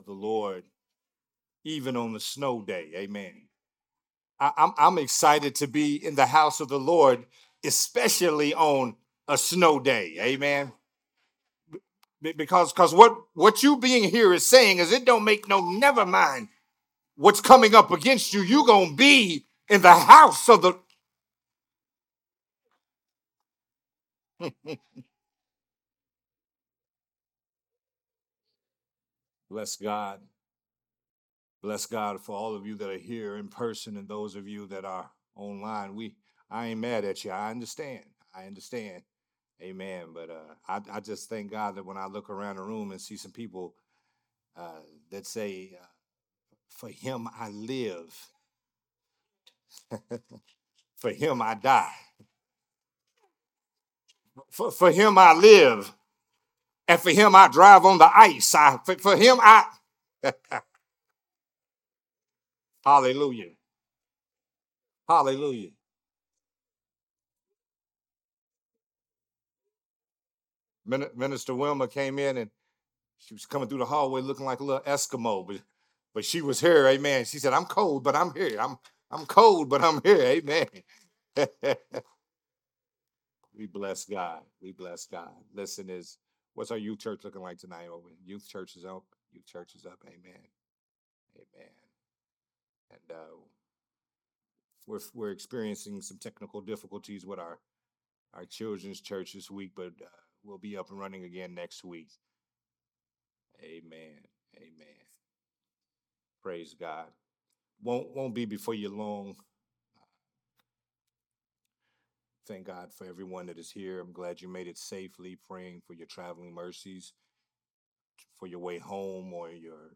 recorded at Growth Temple Ministries on Sunday, December 14, 2025.